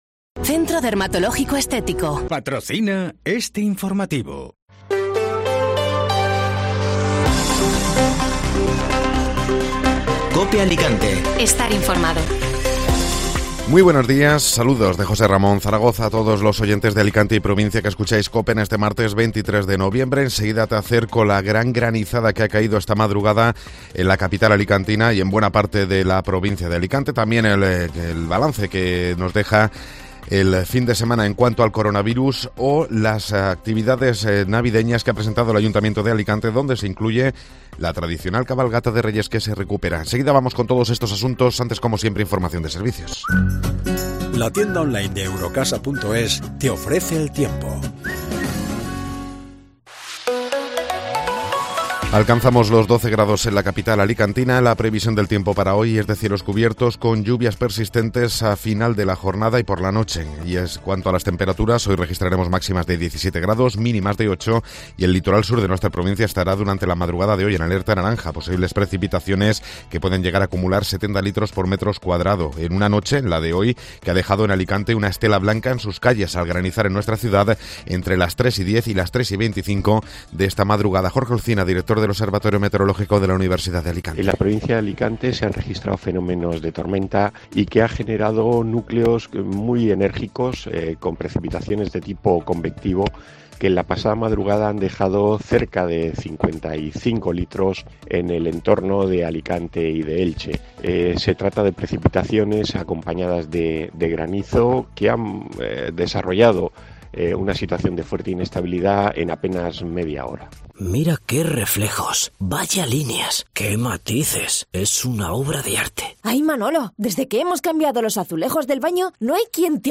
Informativo Matinal (Martes 23 de Noviembre)